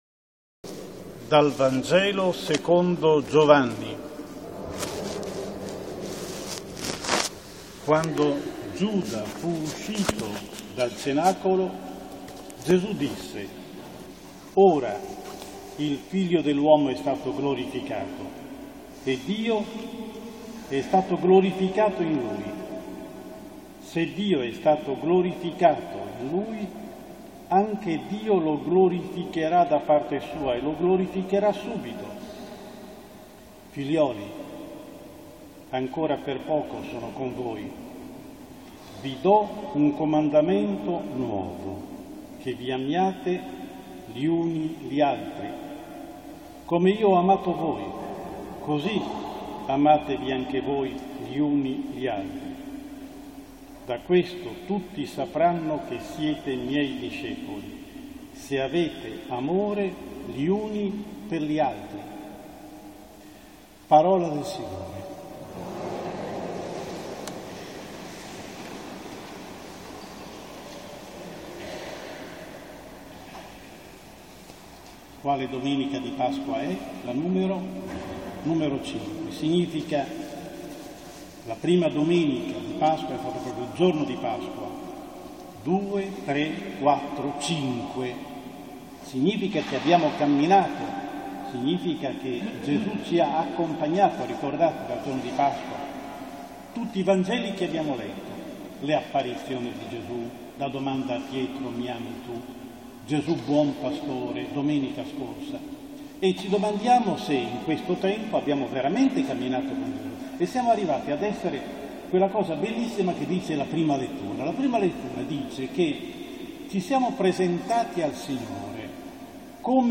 Omelia Domenica V di Pasqua